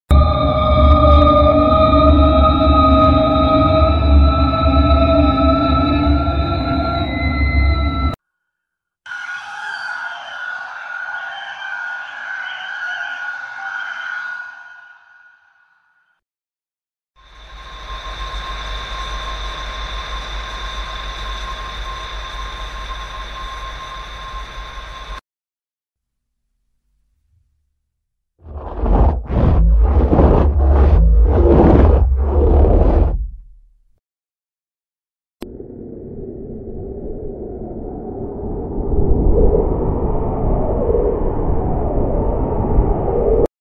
Effetti Sonori: Suoni Paurosi / Sound Effects Free Download
effetti sonori: suoni paurosi /